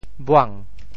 妄 部首拼音 部首 女 总笔划 6 部外笔划 3 普通话 wàng 潮州发音 潮州 bhuang2 文 潮阳 bhuang2 文 澄海 bhuang2 文 揭阳 bhuang2 文 饶平 bhuang2 文 汕头 bhuang2 文 中文解释 潮州 bhuang2 文 对应普通话: wàng ①胡乱，荒诞不合理：轻举～动 | 胆大～为 | ～自尊大 | ～图 | 虚～ | 狂～ | 痴心～想。